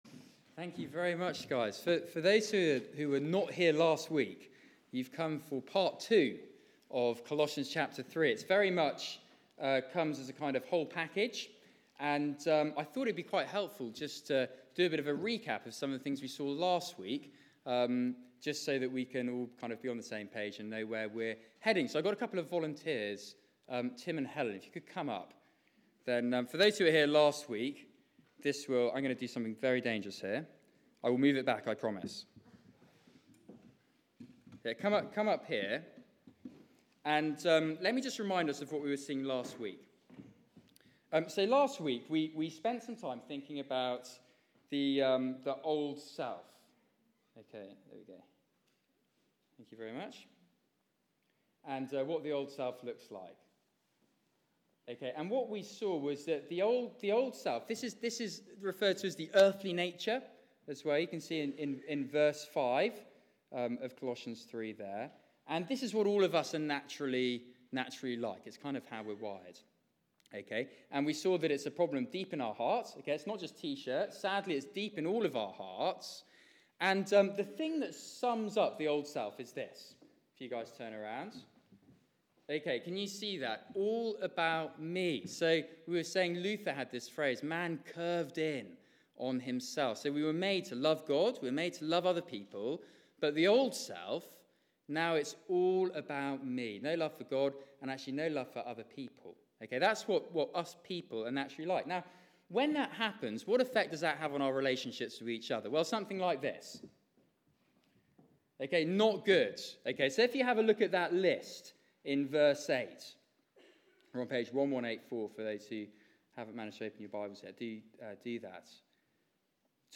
Media for 6:30pm Service on Sun 20th Nov 2016 18:30
Series: Rooted in Christ Theme: Christ and the arrival of the new you Sermon